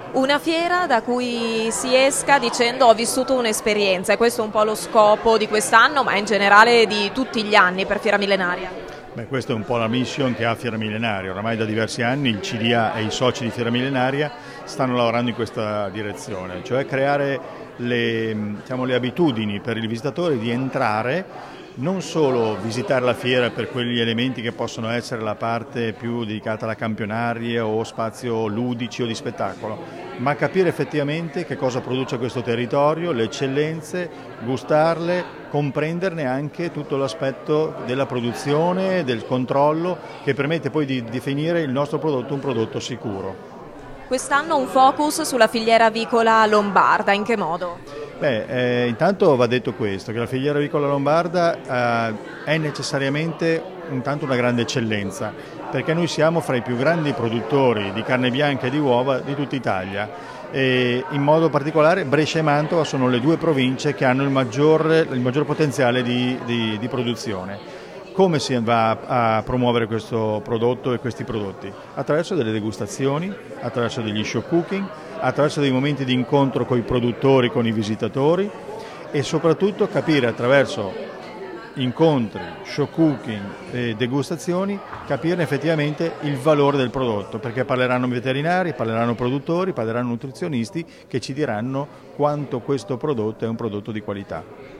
Conferenza stampa di presentazione della Fiera Millenaria di Gonzaga, edizione 2019